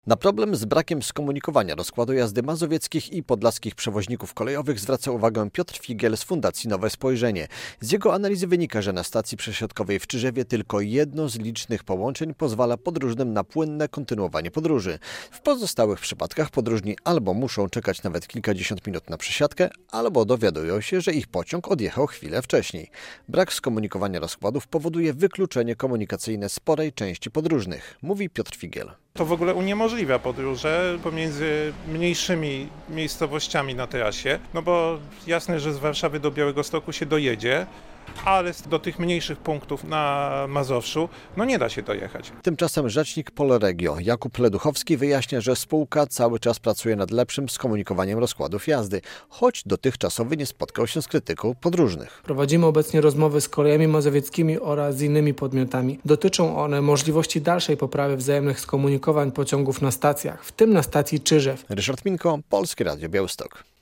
Radio Białystok | Wiadomości | Wiadomości - Problemy z przesiadkami na trasie Białystok-Warszawa